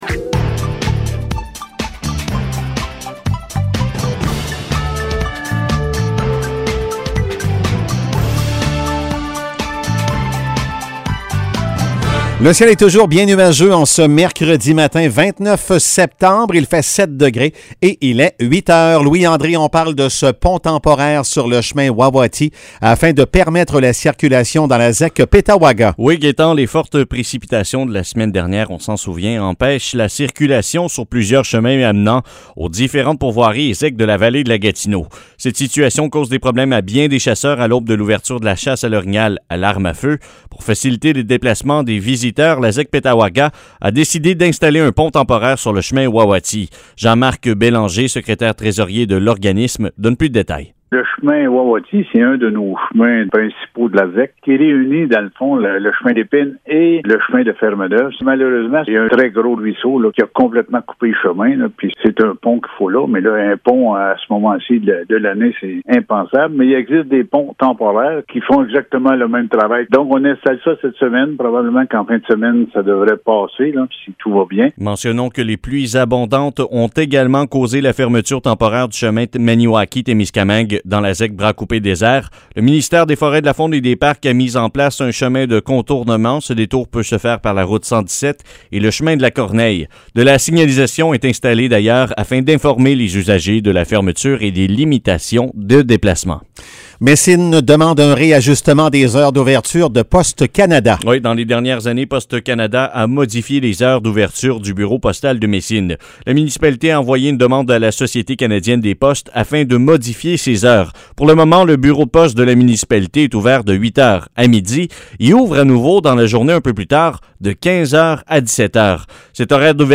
Nouvelles locales - 29 septembre 2021 - 8 h